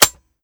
lowammo_indicator_automatic.wav